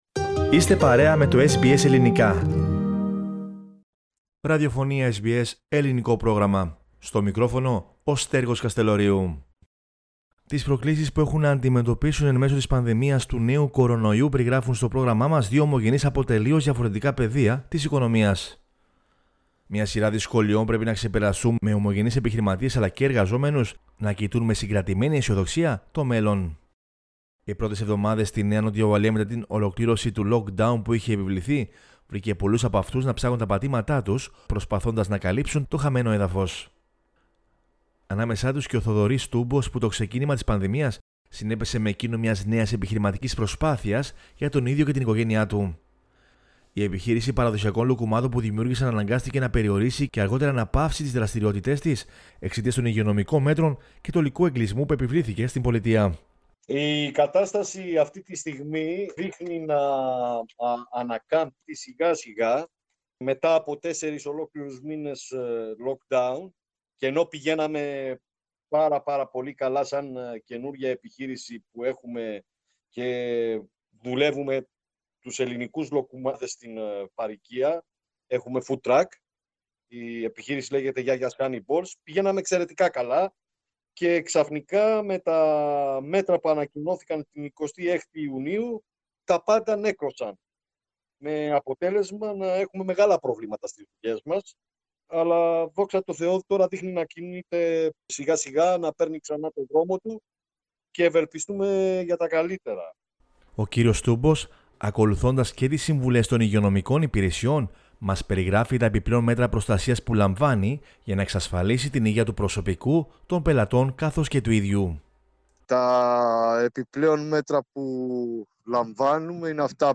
Τις προκλήσεις που έχουν να αντιμετωπίσουν εν μέσω της πανδημίας του νέου κορωνοϊού, περιγράφουν στο Ελληνικό Πρόγραμμα της Ραδιοφωνίας SBS, δύο ομογενείς από τελείως διαφορετικά πεδία της οικονομίας.